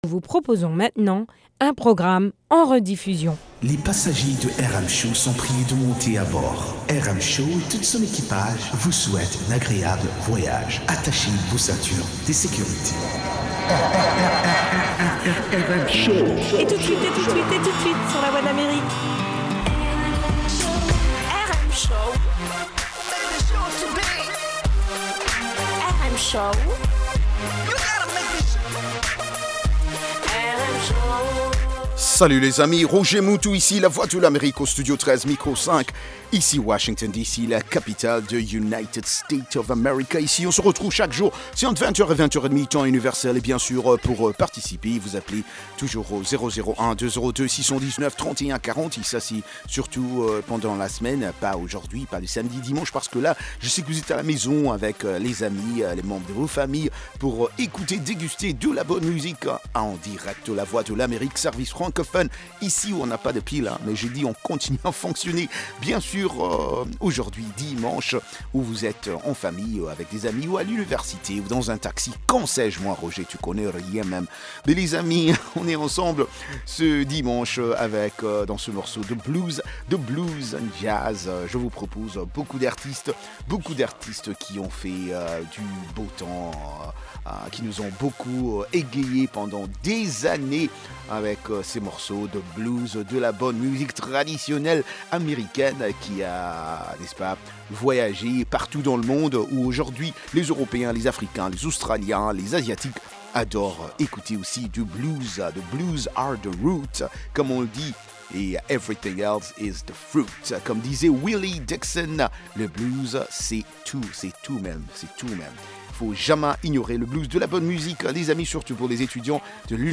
Blues and Jazz Program